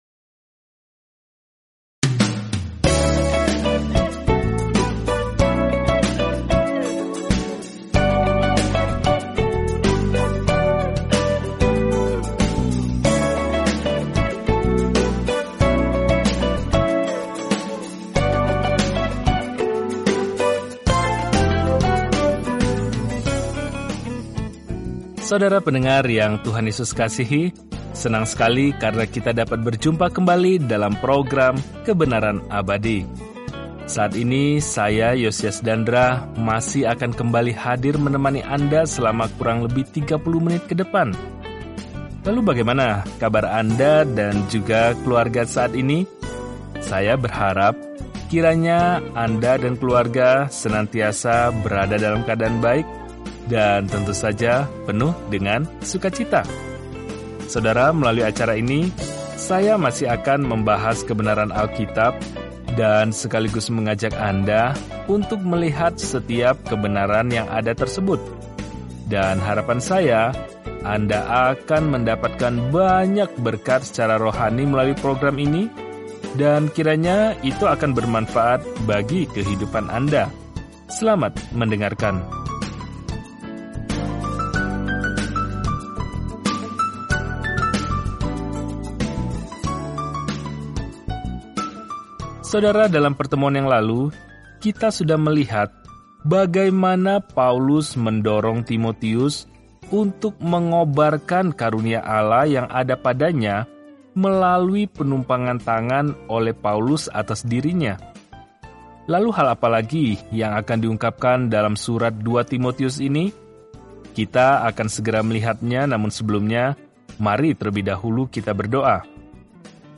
Eksplorasi Yehezkiel setiap hari sambil mendengarkan pelajaran audio dan membaca ayat-ayat tertentu dari firman Tuhan.